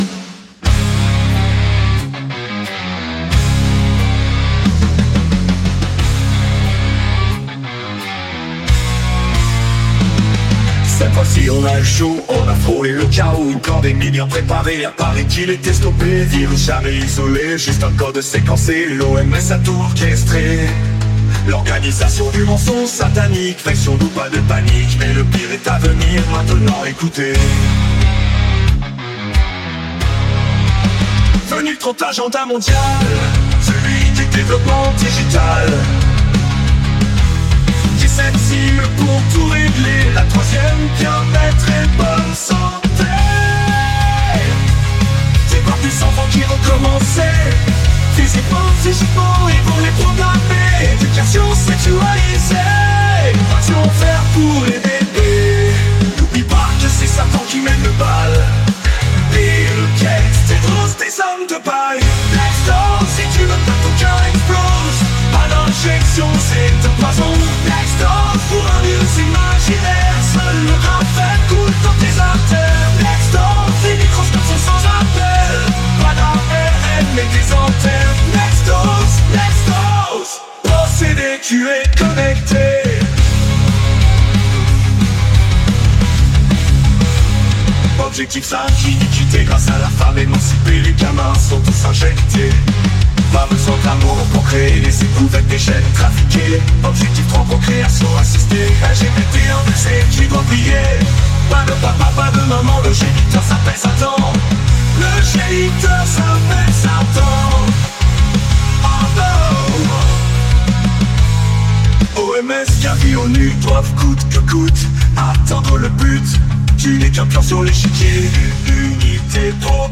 Download the music in 432 Hz (audio wav - 70,8 Mo)